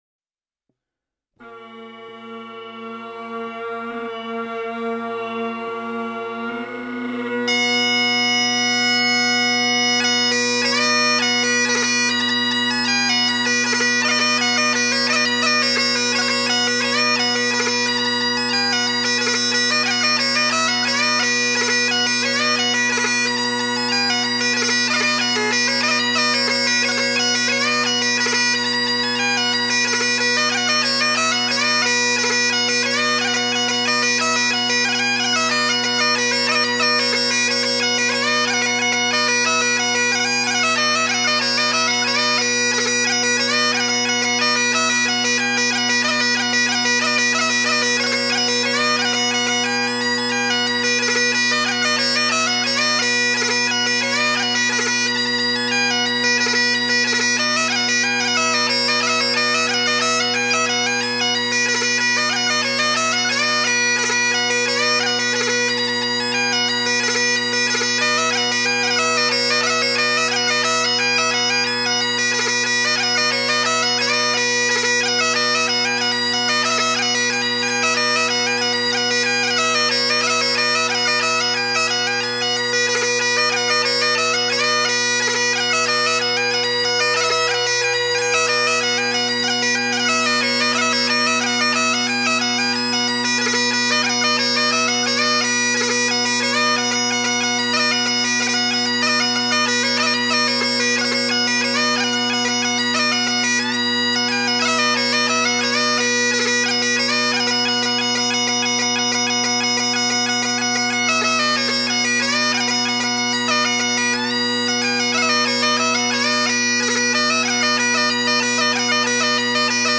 The album was recorded in Houffalize, Belgium.
The first would be a set of hornpipes.
and the ambience of the room was good.
There are quite a few gracenotes going on.